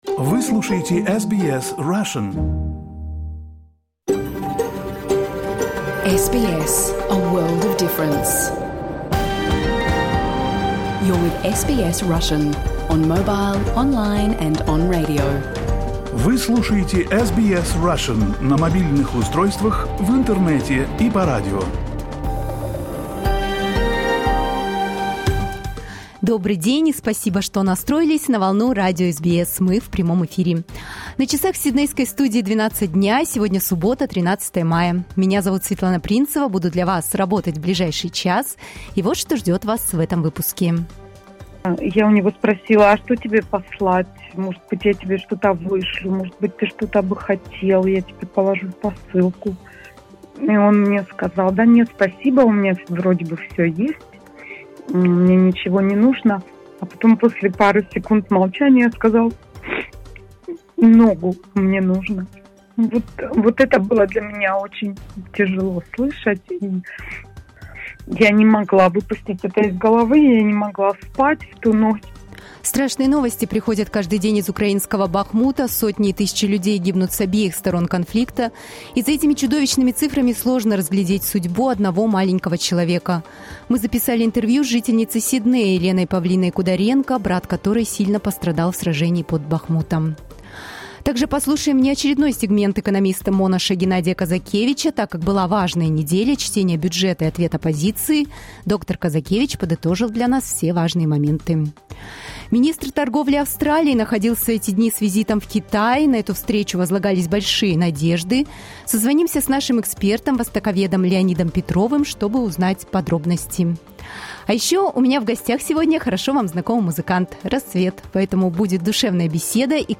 You can listen to SBS Russian program live on the radio, on our website and on the SBS Audio app.